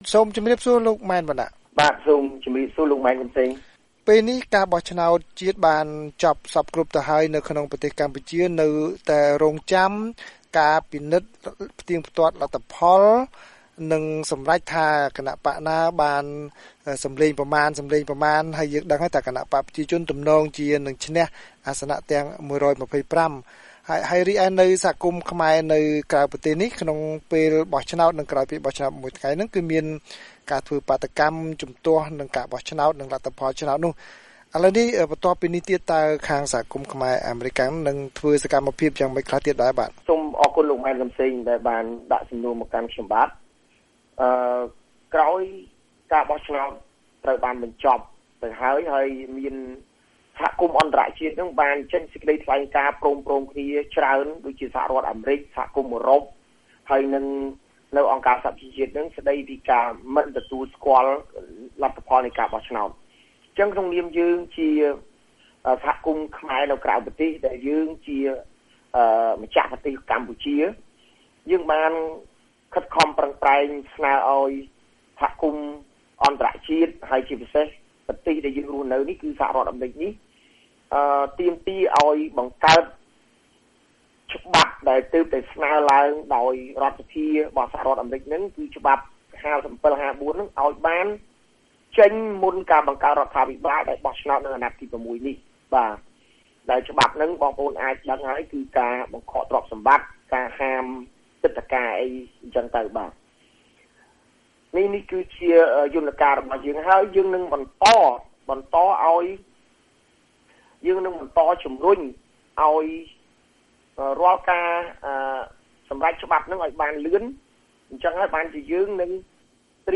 បទសម្ភាសន៍ VOA៖ អ្នកគាំទ្របក្សសង្គ្រោះជាតិជំរុញព្រឹទ្ធសភាអនុម័តសេចក្តីព្រាងច្បាប់៥៧៥៤